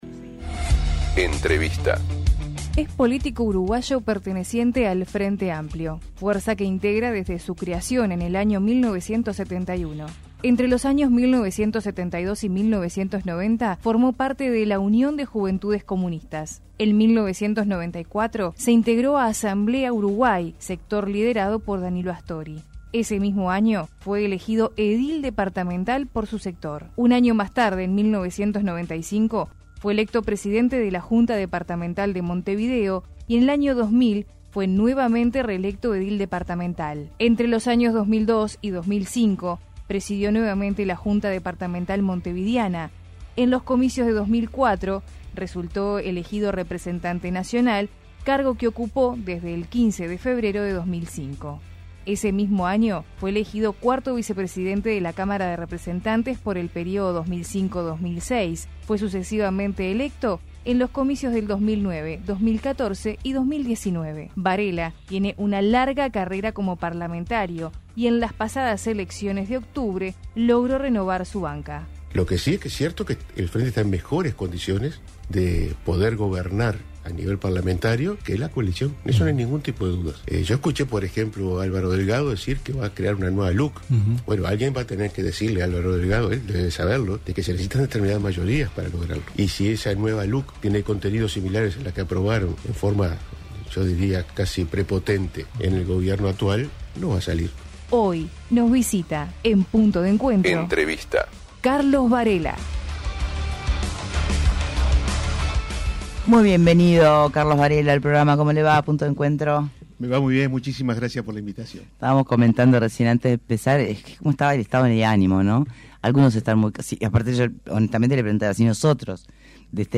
Entrevista a Carlos Varela